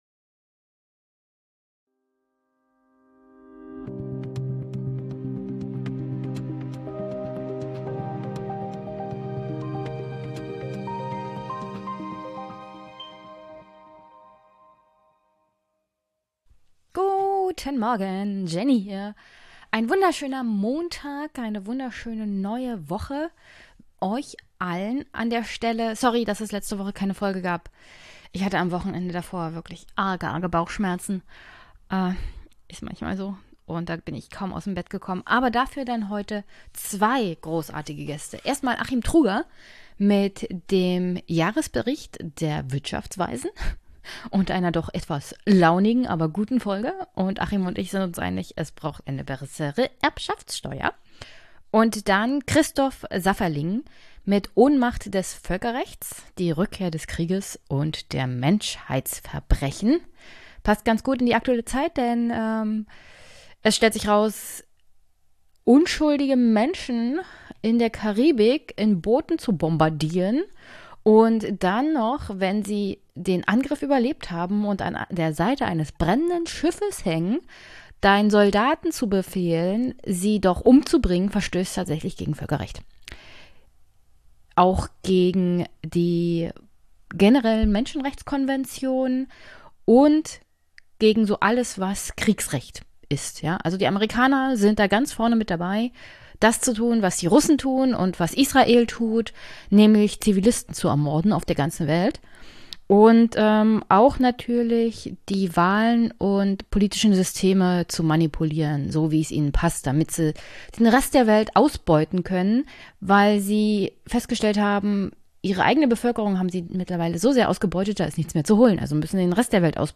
Heute spreche ich mit Achim Truger, dem Ökonom und Mitglied des Sachverständigenrats Wirtschaft, über das Jahresgutachten 2025/26 und die Notwendigkeit von Reformen bei der Erbschaftsteuer.